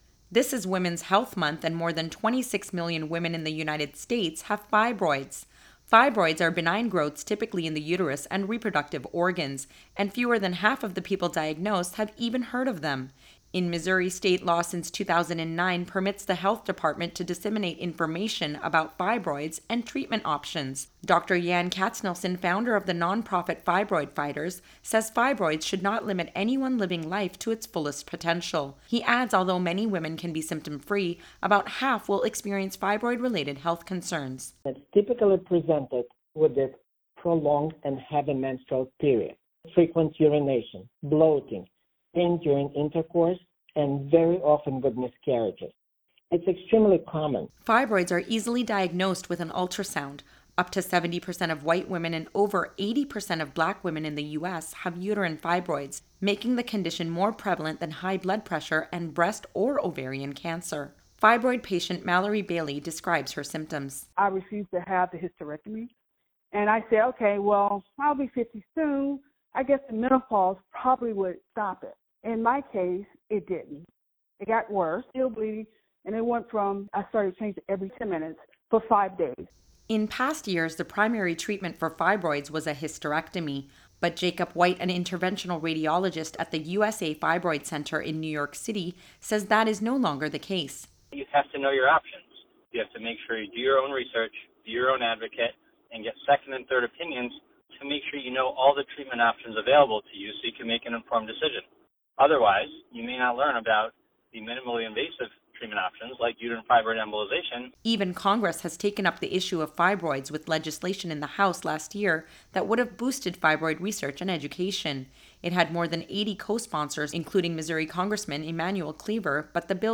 A radio broadcast on the Public News Service
for a conversation about fibroids.
PNS-Radio-Interview.mp3